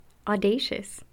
Other forms: The adjective is "audacious," pronounced "aw DAY shuss."
audacious.mp3